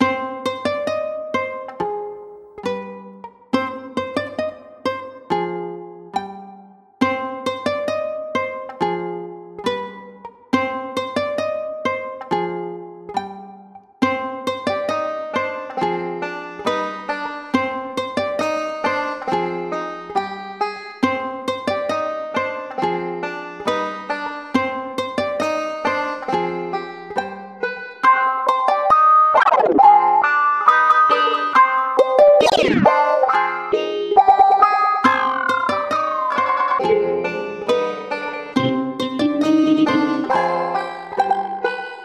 Мелодия для домры с электронной зацикленной концовкой